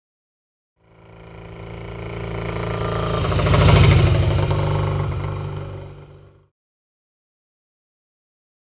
Motorcycle; By; Triumph Twin Up And Close Past At 40 Mph.